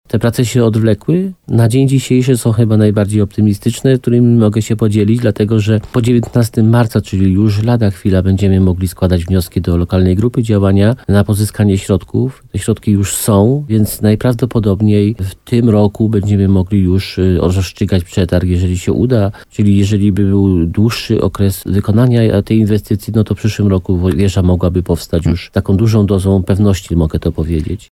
Dopiero teraz uda się dopiąć kwestie finansowania – mówi wójt gminy Limanowa, Jan Skrzekut.